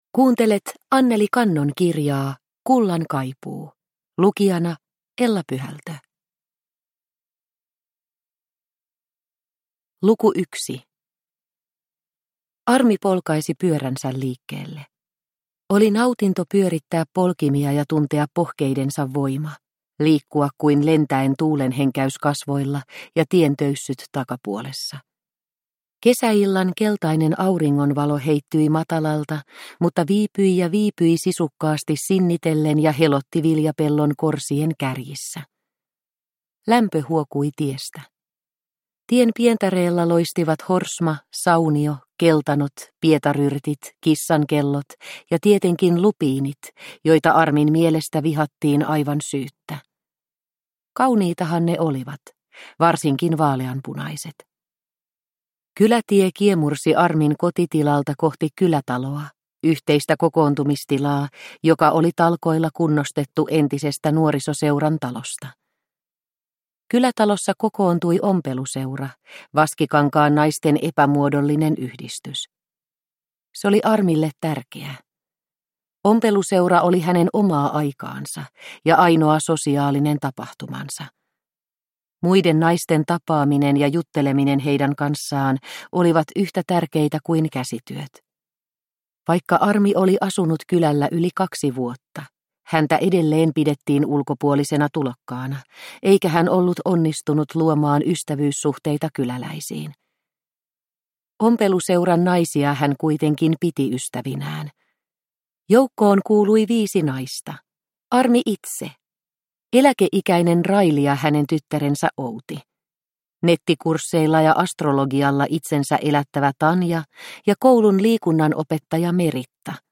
Kullan kaipuu – Ljudbok – Laddas ner